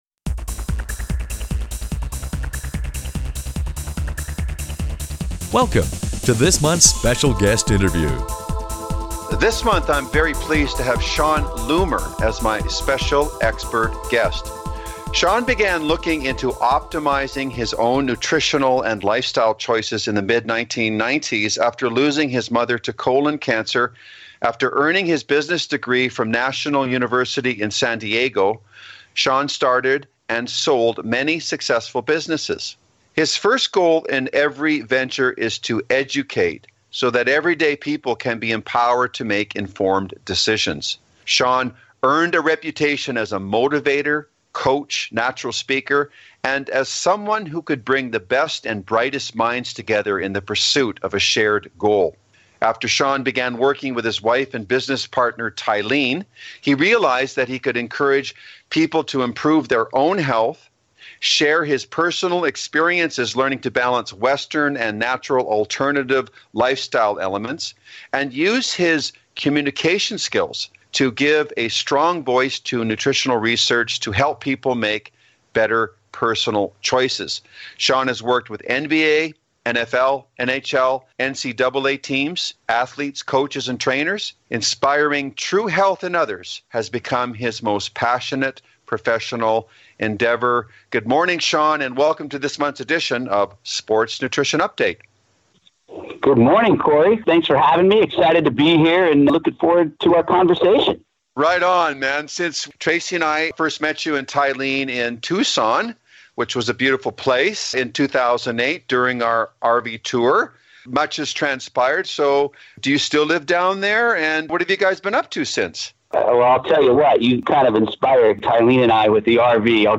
Special Guest Interview Volume 16 Number 10 V16N10c